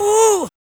4506R SHOUT.wav